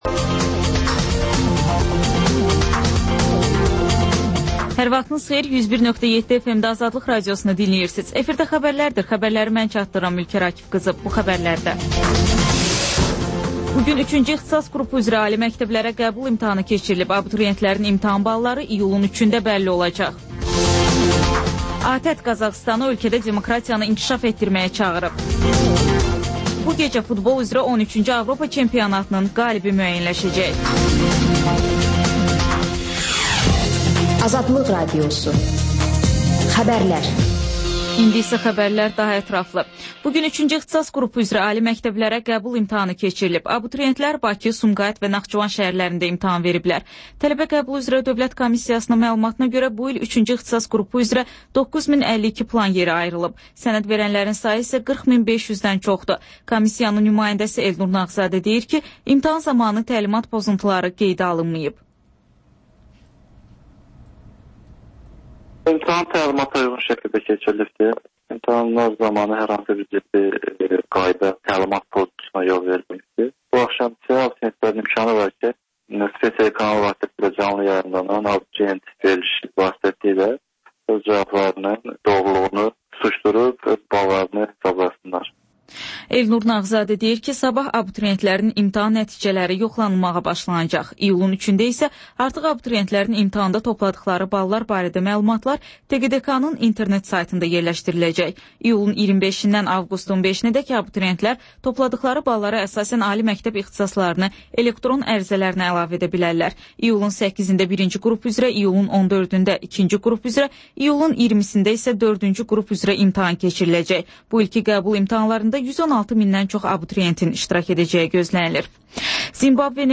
Xəbərlər, İZ: Mədəniyyət proqramı və TANINMIŞLAR rubrikası: Ölkənin tanınmış simaları ilə söhbət